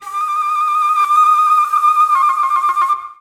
02-Flute 2.wav